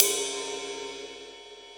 CM2 RIDE   2.wav